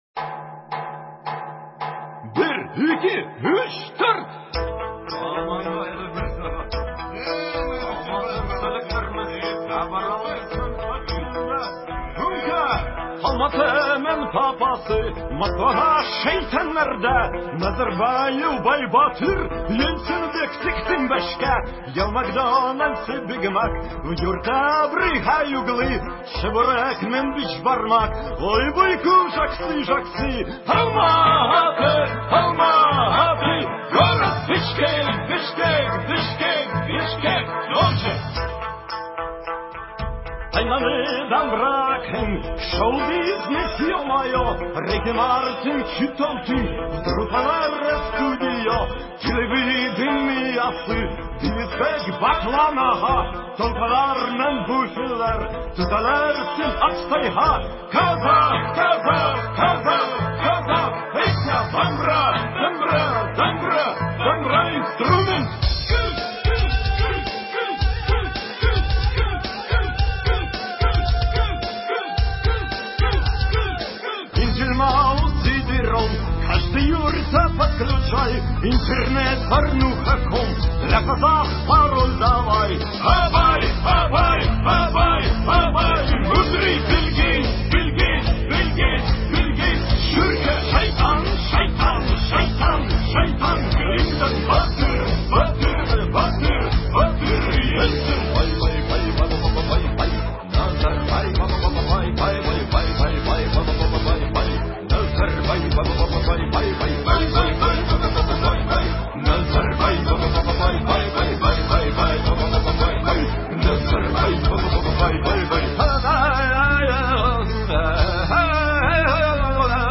Казахская песня